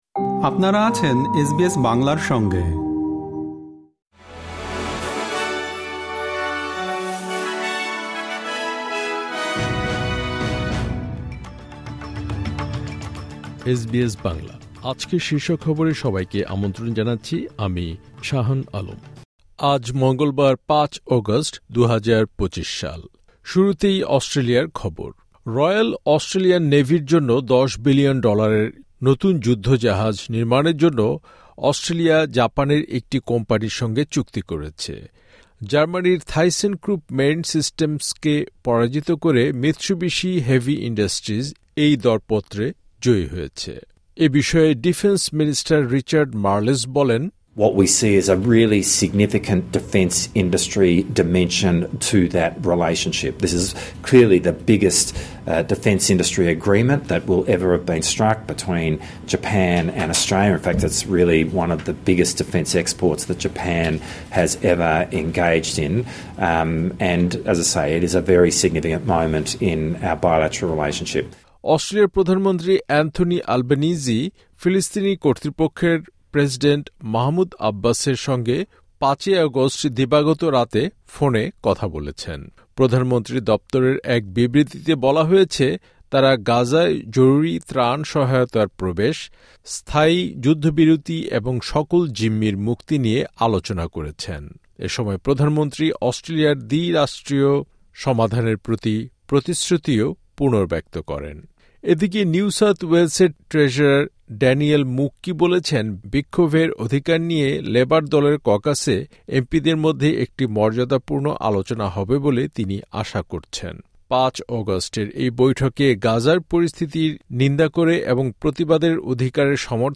এসবিএস বাংলা শীর্ষ খবর: ৫ অগাস্ট, ২০২৫